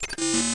ScannerUse2.ogg